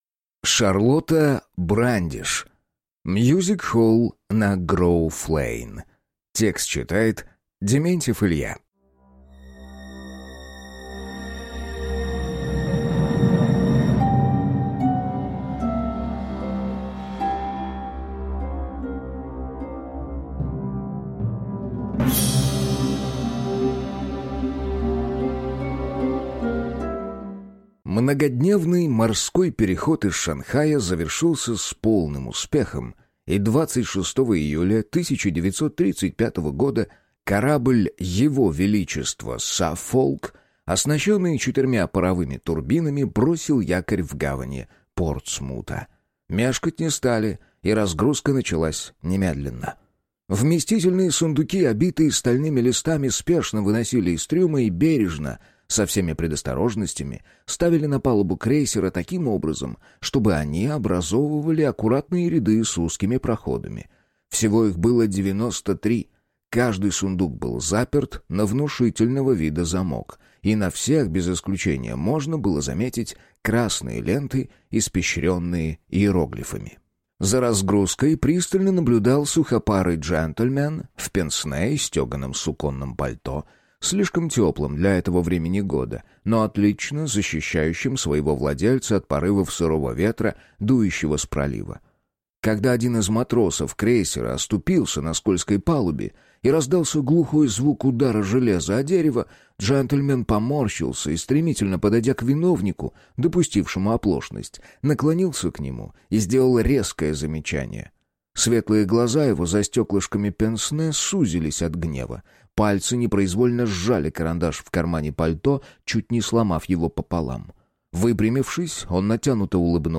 Аудиокнига Мюзик-холл на Гроув-Лейн | Библиотека аудиокниг
Прослушать и бесплатно скачать фрагмент аудиокниги